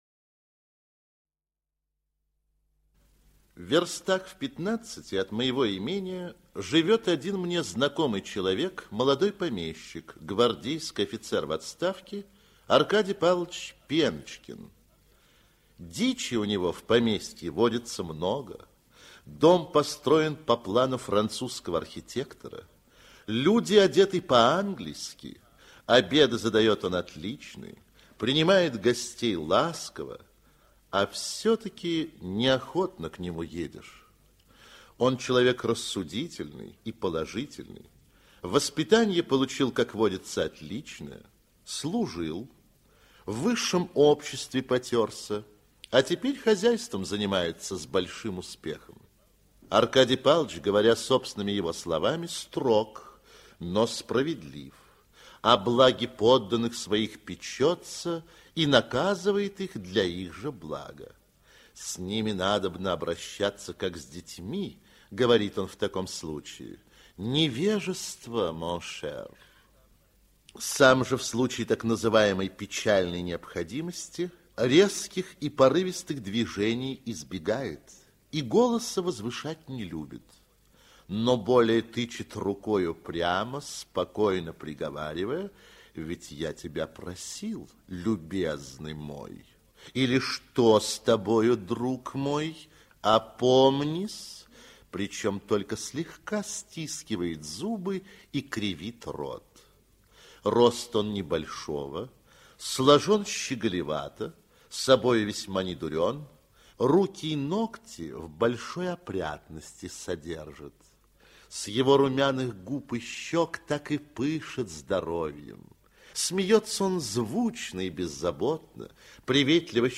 Бурмистр - аудио рассказ Тургенева И.С. Рассказ о беспросветно тяжелой жизни крепостных крестьян на Руси...